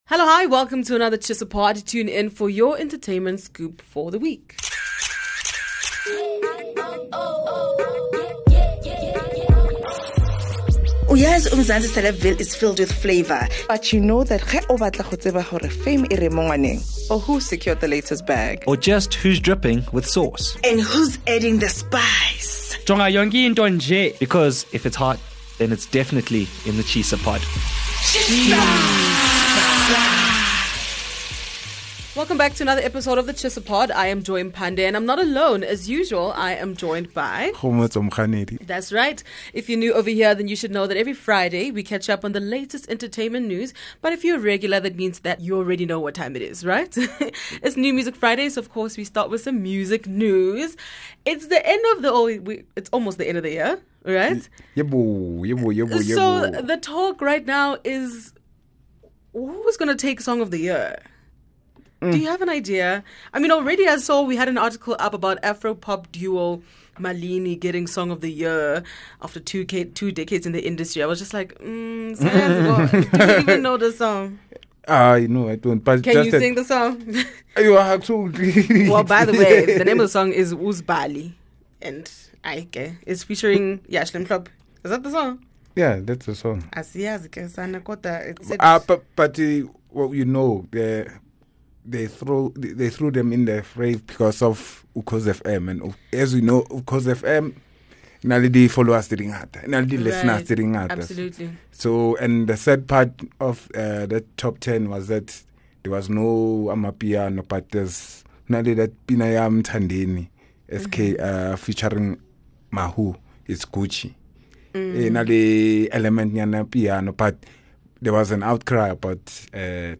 The weekly political show by top journalists of the Sunday Times discussing the week's biggest stories, toughest topics in a tight and guided conversation.